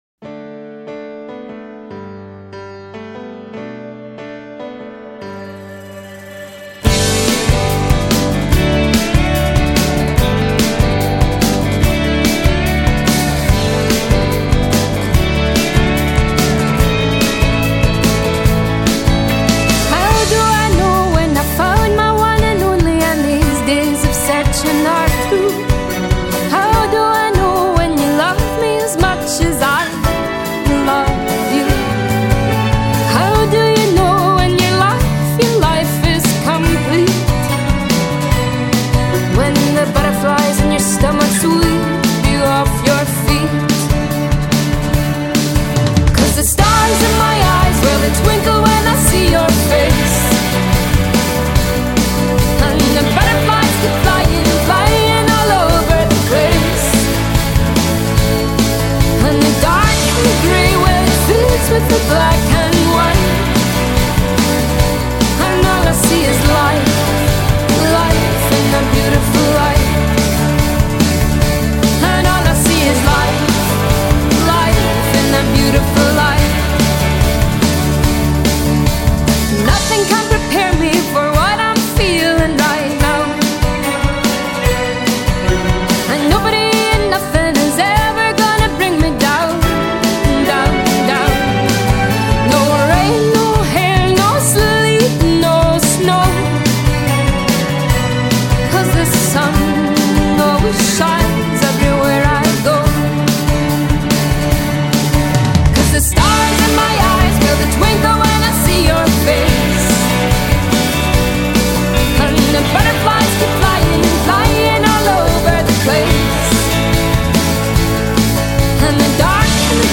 Жанр: pop